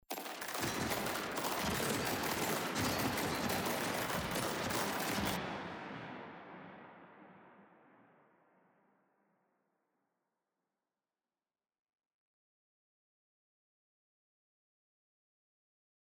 granular_04：更にSIZEを500%、RAMDOM PITCHを50%に設定した状態。
granular_05：エフェクトにCLUSTER DELAYを使用して仕上げた状態。
ここではCLUSTER DELAYを使用して、独特の空間演出を行なってみました。
granular_05.mp3